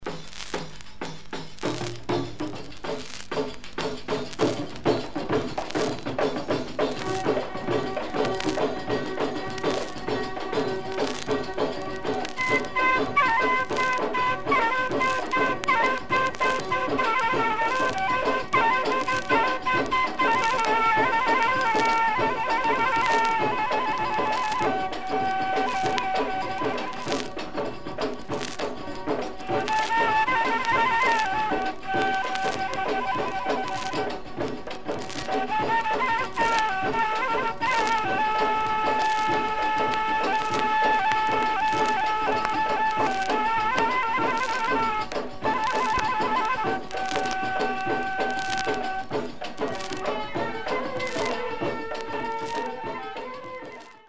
Very rai proto rai, disc and cover in very good condition.